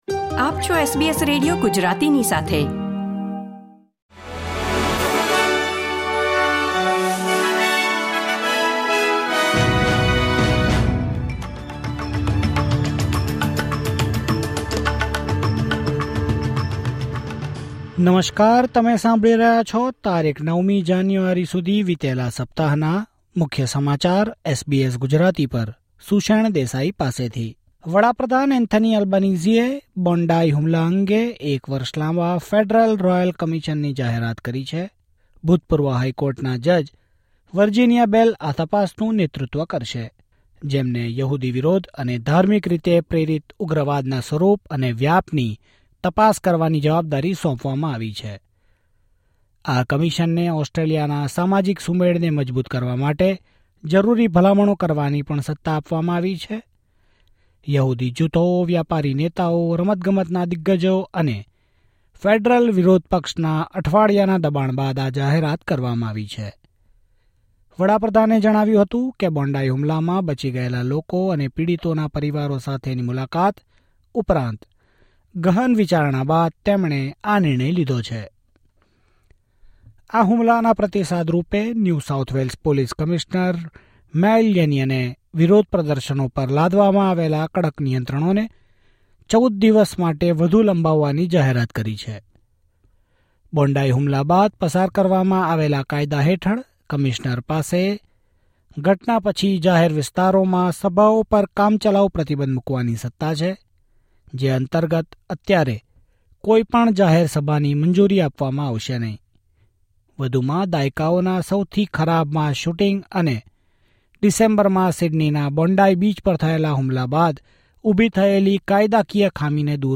Australian Weekly News Update